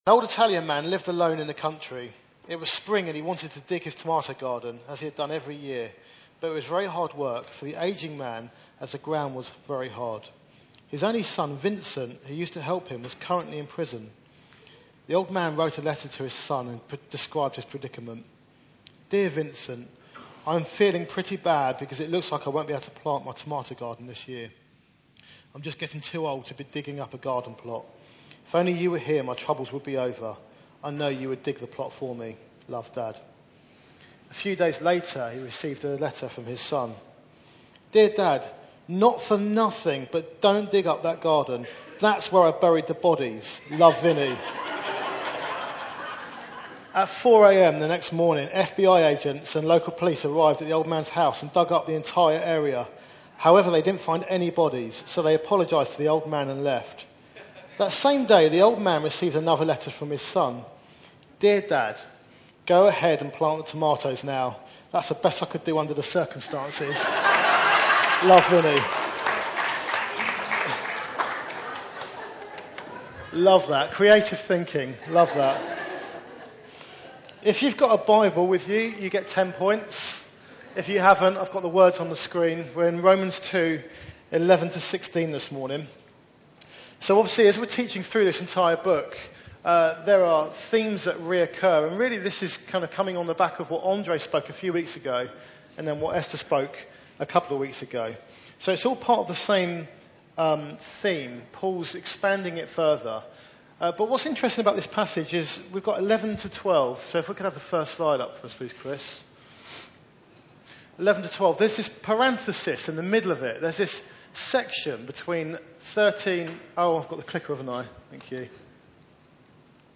Service Type: Sunday Meeting